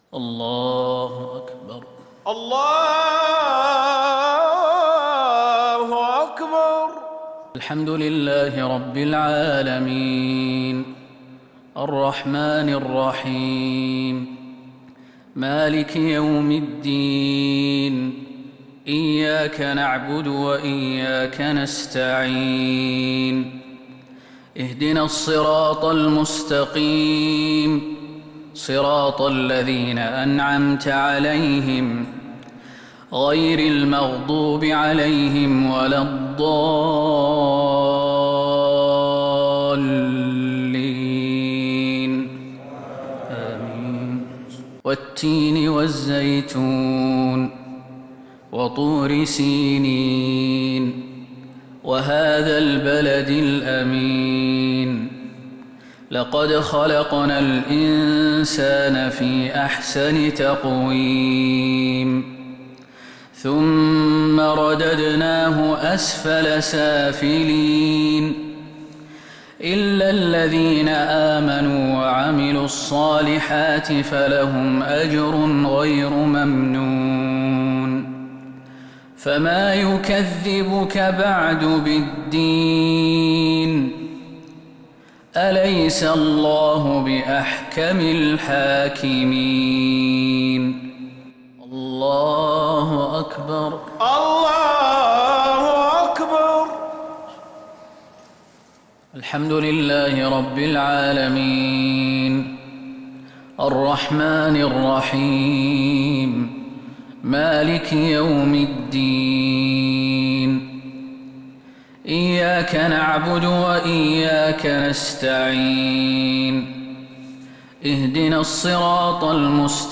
صلاة المغرب للقارئ خالد المهنا 8 ربيع الأول 1442 هـ
تِلَاوَات الْحَرَمَيْن .